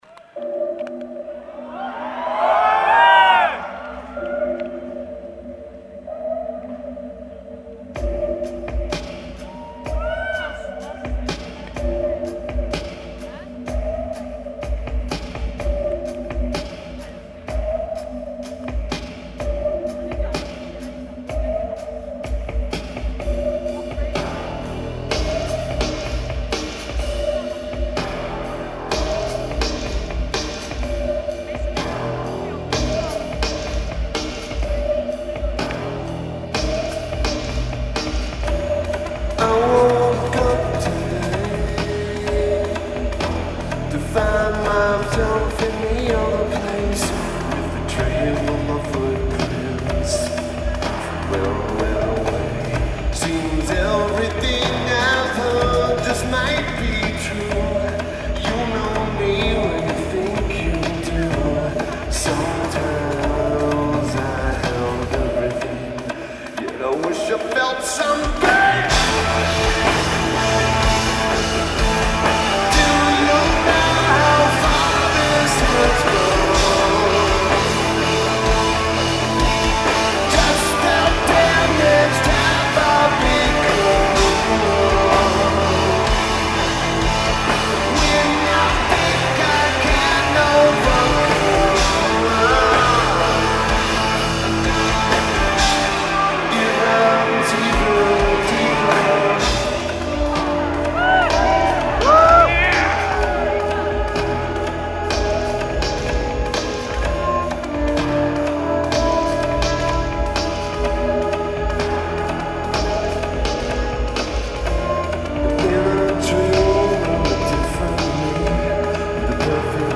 Lineage: Audio - AUD (Sony ECM-719 + Zoom H2N)